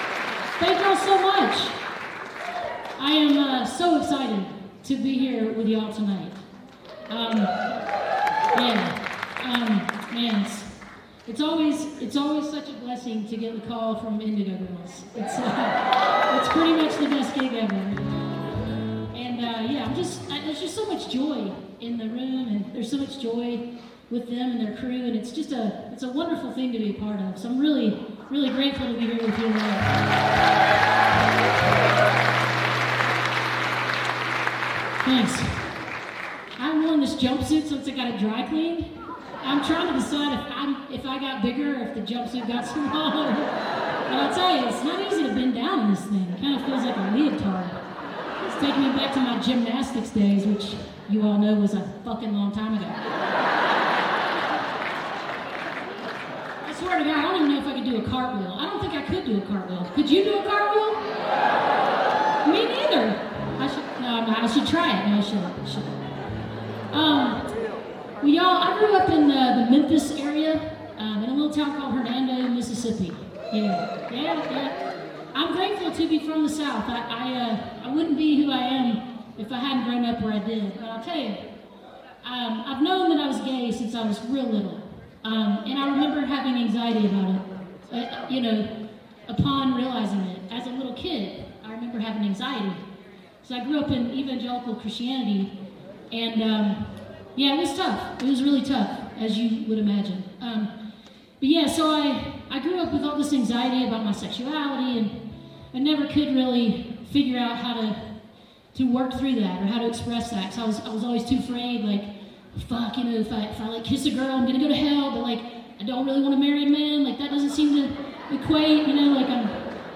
(band show)
(captured from a web stream)